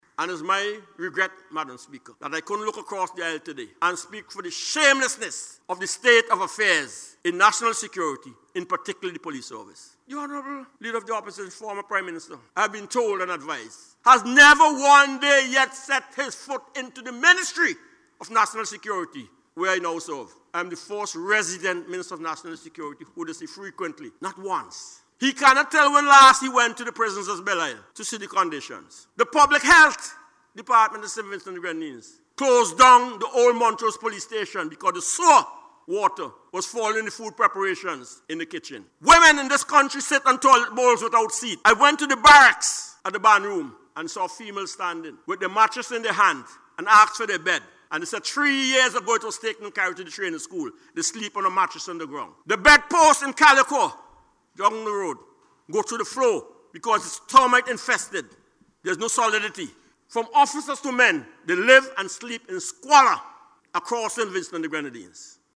In his presentation during the budget estimates debate, Minister Leacock outlined deplorable conditions, including inadequate facilities and substandard living quarters for officers, underscoring the urgent need for comprehensive reform.